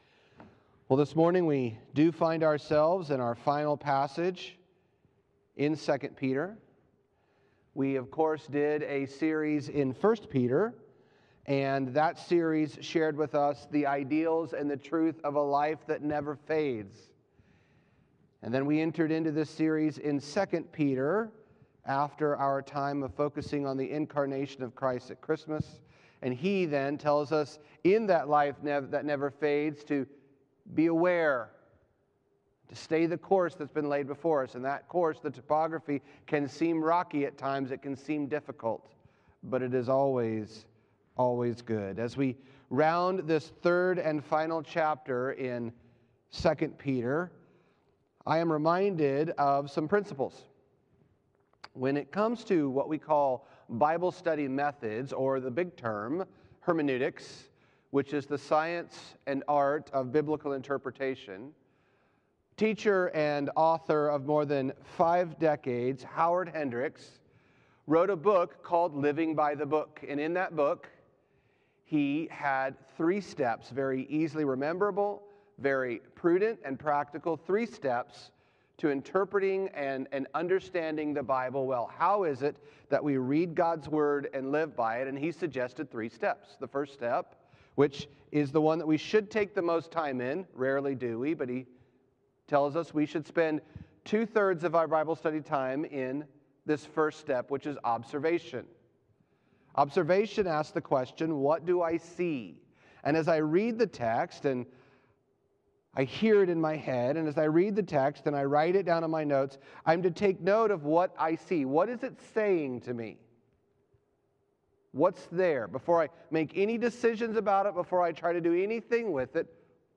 Sermon Series: 2 Peter – The perspective of heaven